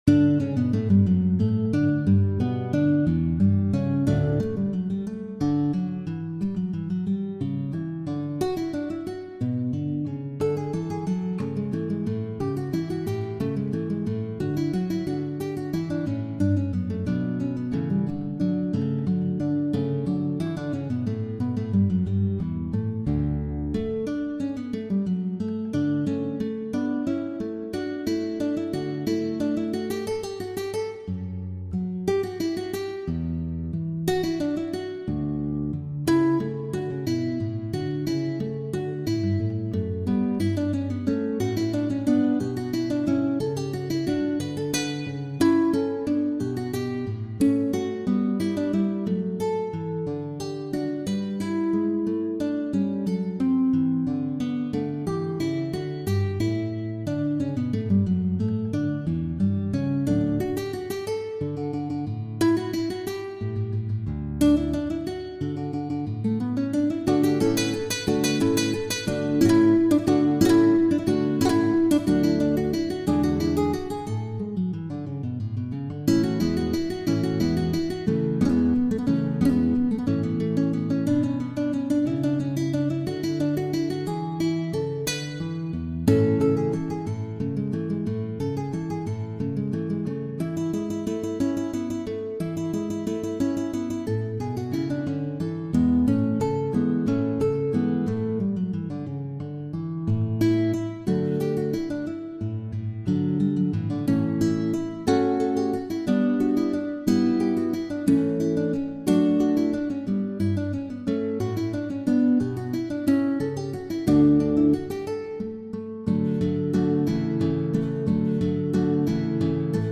Concerto-en-re-majeur-3eme-mouvement.mp3